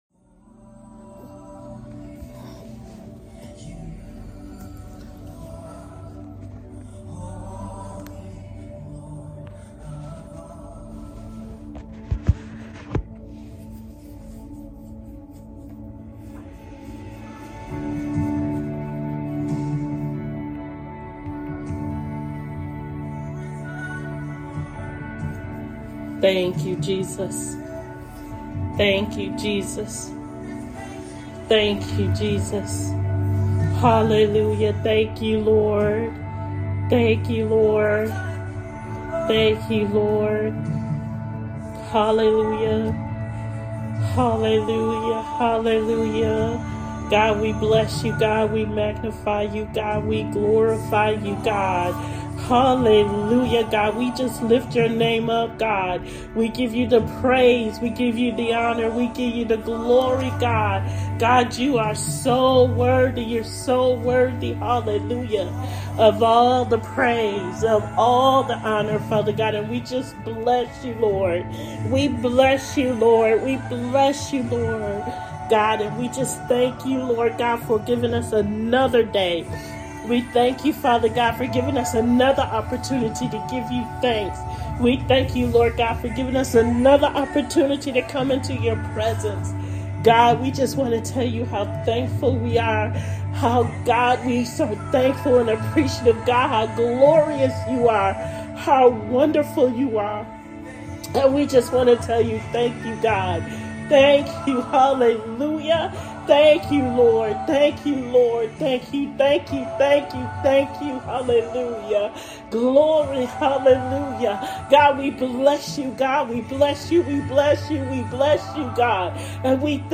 Thursday Night Intercessory Prayer